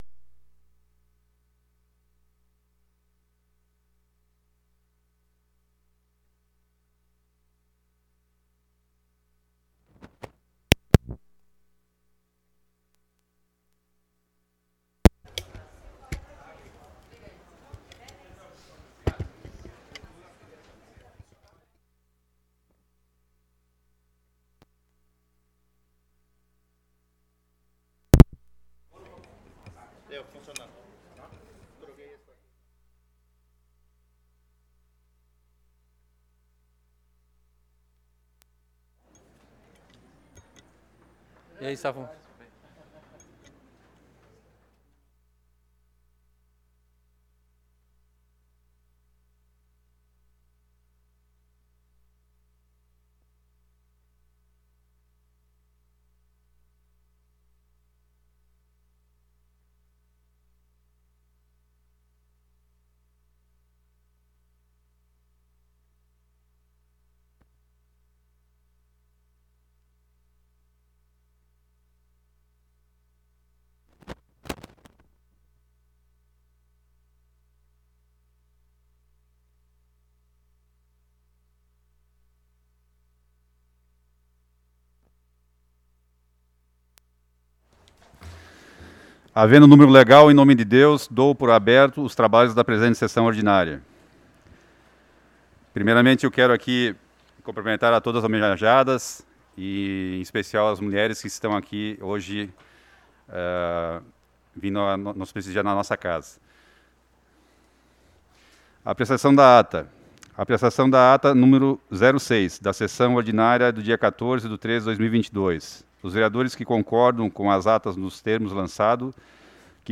Sessão Ordinária do dia 28 de Março de 2022 - Sessão 07